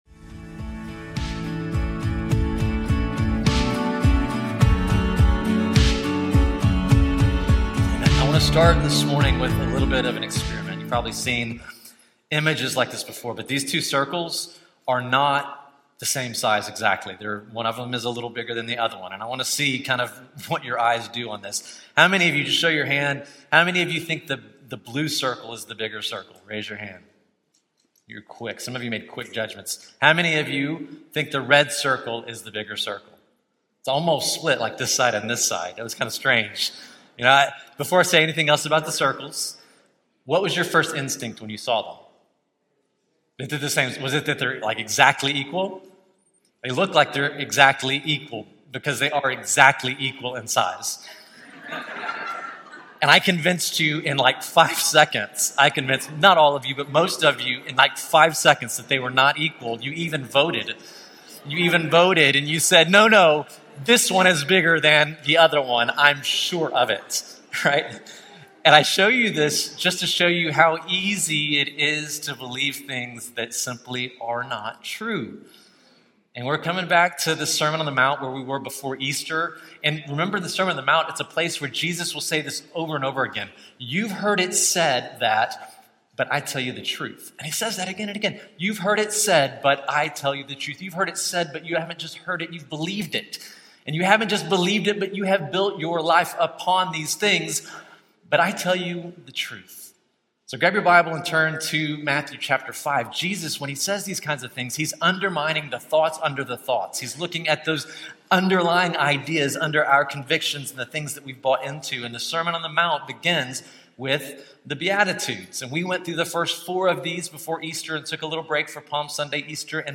With insight, humor, and depth, the speaker invites listeners to move beyond checklist Christianity and into a Spirit-led life marked by mercy, humility, and transformation.